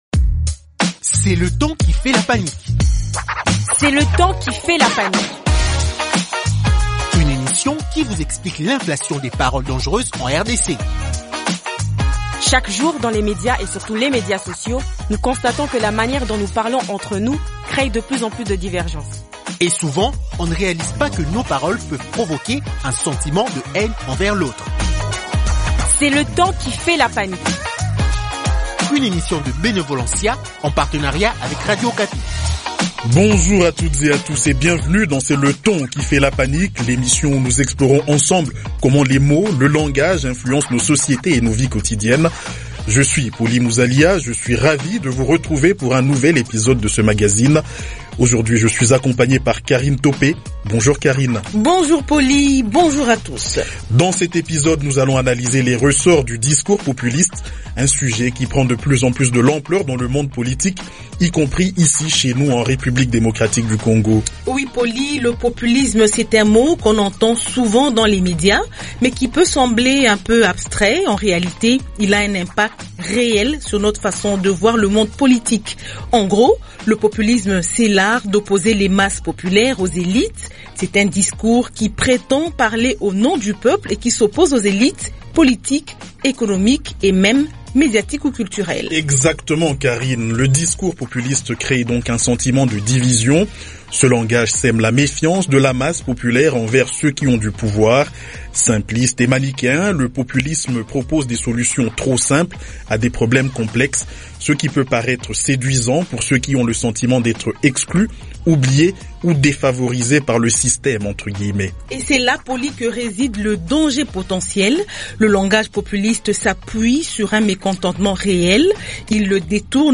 Pour en parler, deux invités :